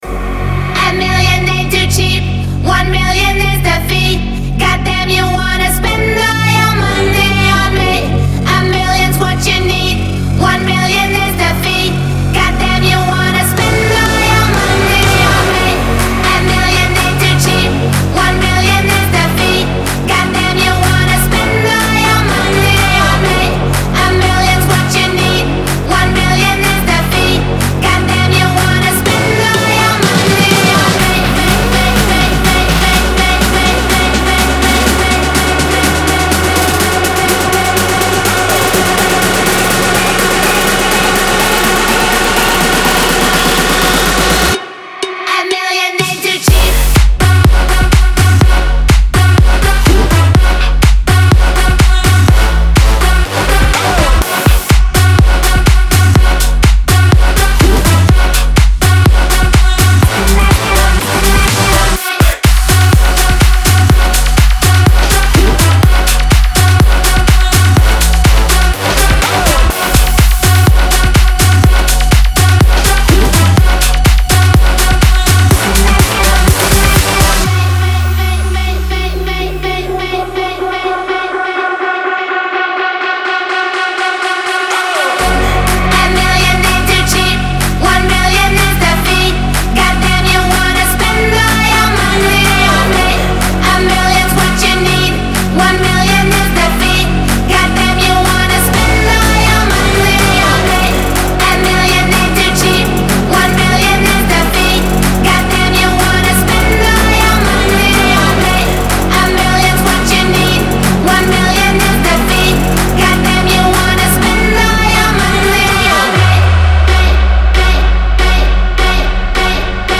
клубные треки